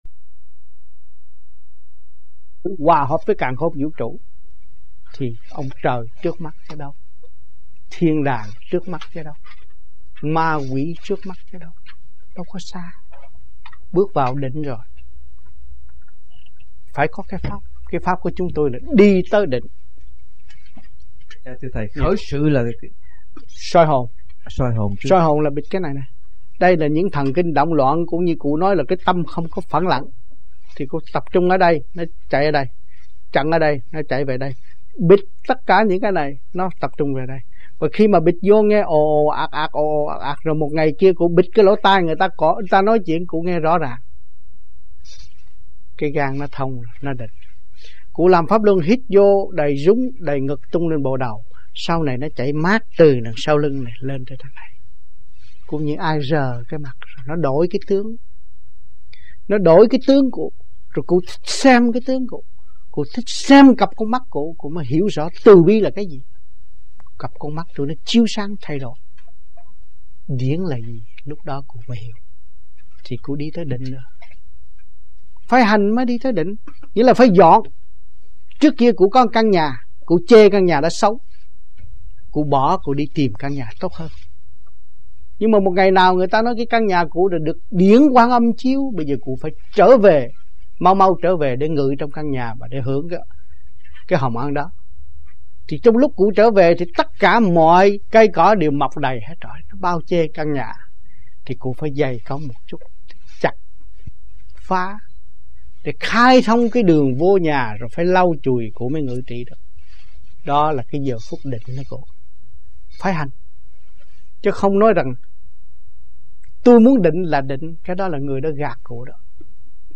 1980 Đàm Đạo
1980-11-11 - NANTERRE - THUYẾT PHÁP 05